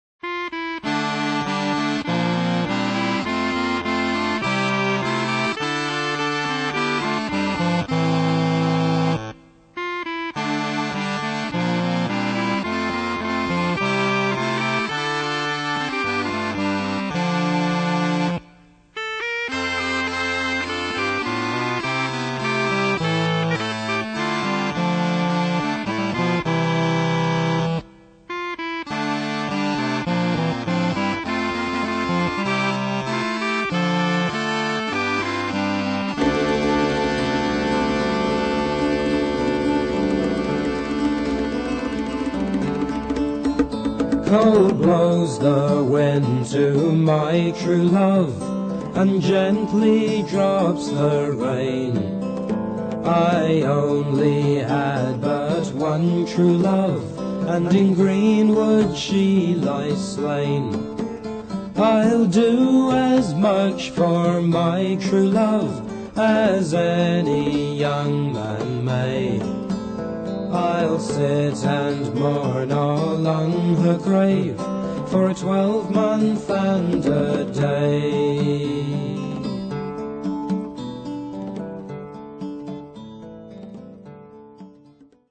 Stereo, 1:24, 22 Khz, (file size: 666 Kb).